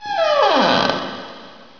doorcreak.wav